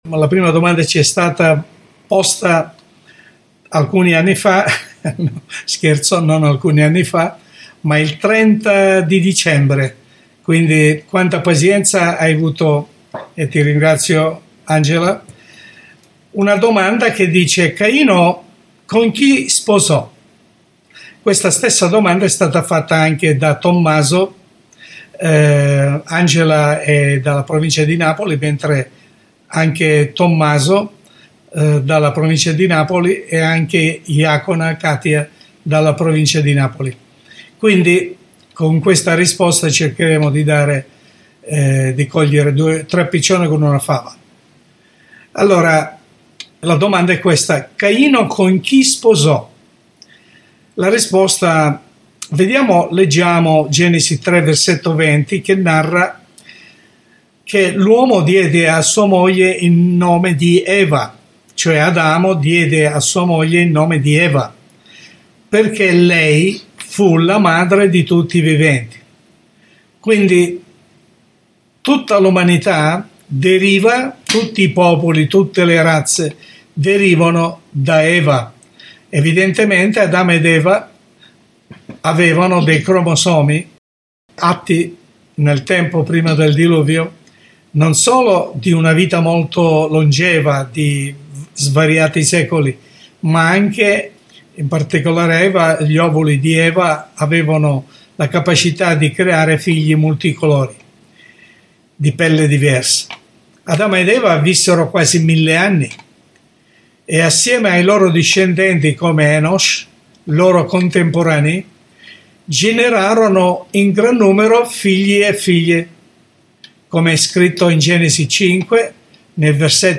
Studio Biblico Pastorale